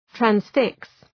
Προφορά
{træns’fıks}